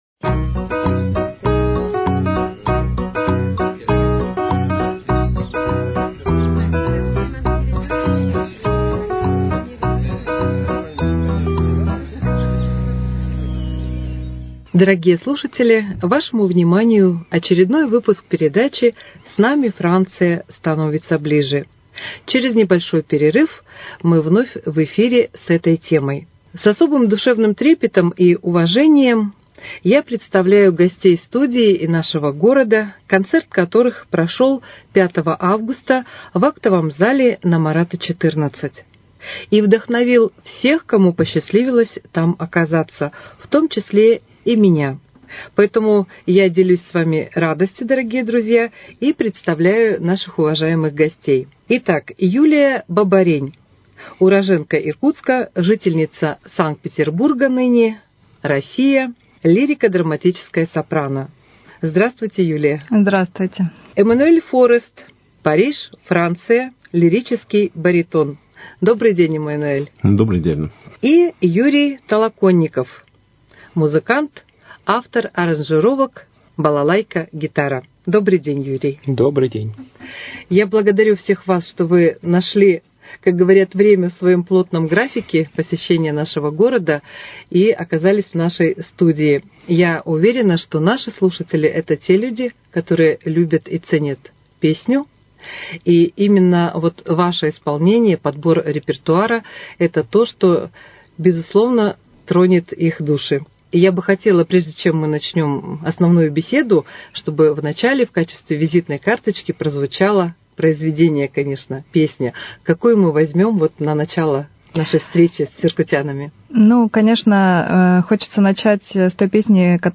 5 августа в нашем городе прошёл концерт в рамках проекта «Белые Мосты». Предлагаем вашему вниманию беседу с его участниками и музыкальным сопровождением.